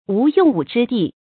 无用武之地 wú yòng wǔ zhī dì
无用武之地发音